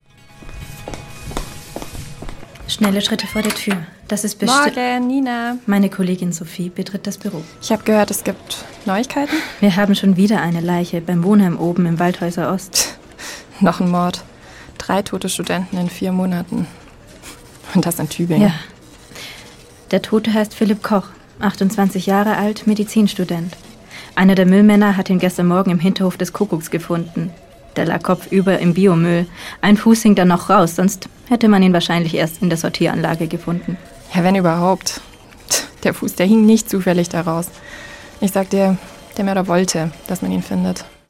Hörspiel IV